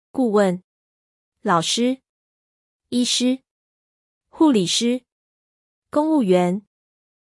コンサルタント 顧問 gù wèn ビジネスコンサル、法律顧問など幅広い。